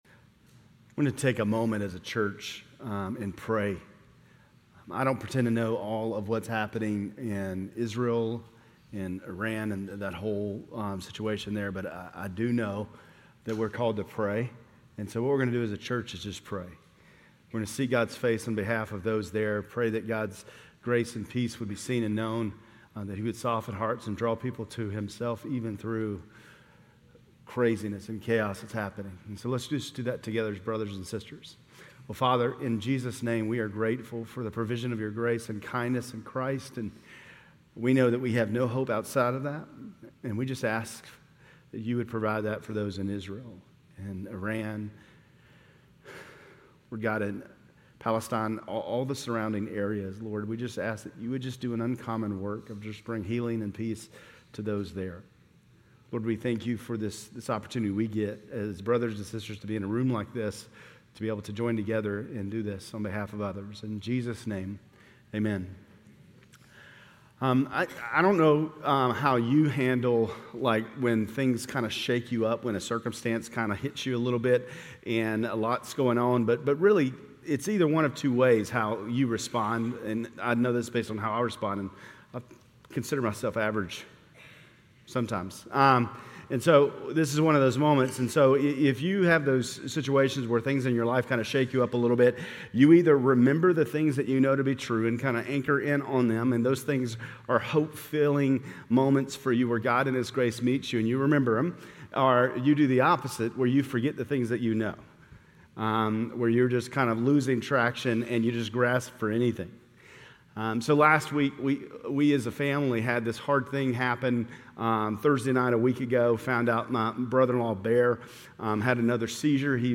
Grace Community Church Lindale Campus Sermons Galatians 2:1-14 Apr 15 2024 | 00:33:24 1x 00:00 / 00:33:24 Subscribe Share RSS Feed Share Link Embed